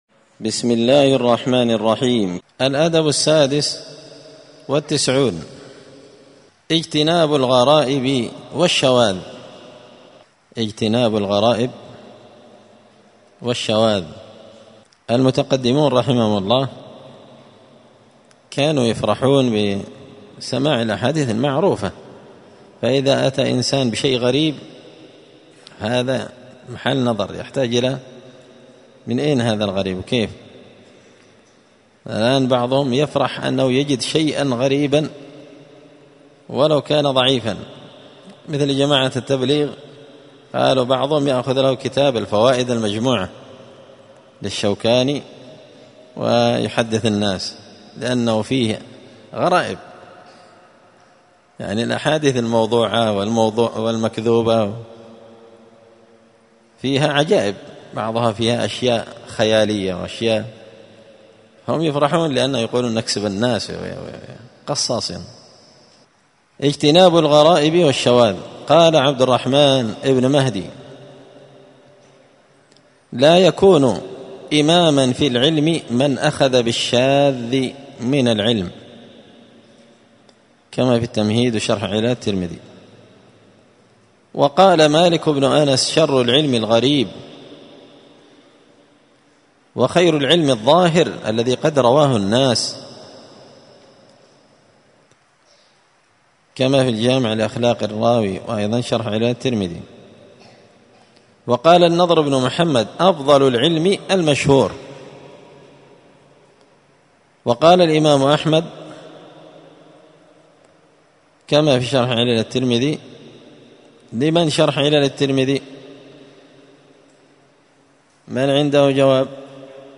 الخميس 4 ربيع الثاني 1445 هــــ | الدروس، النبذ في آداب طالب العلم، دروس الآداب | شارك بتعليقك | 72 المشاهدات